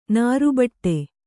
♪ nāru baṭṭe